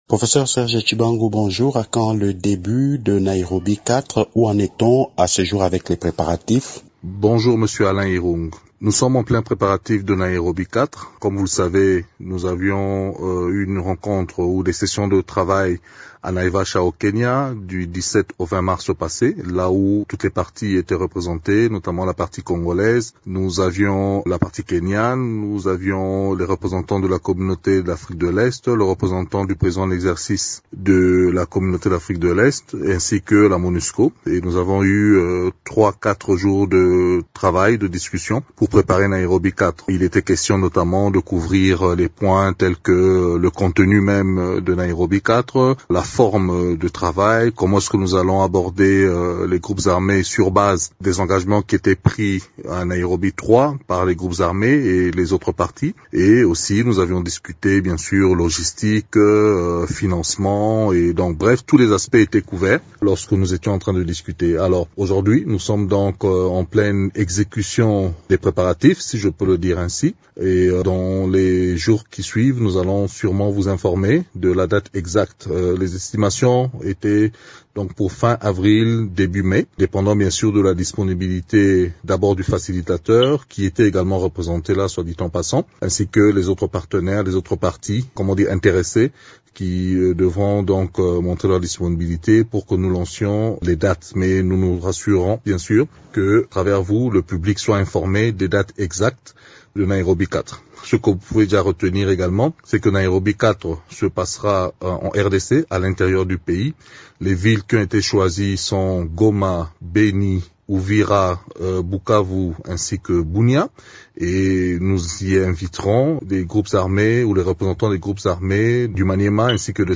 interview_prof_serge_tshibangu_web.mp3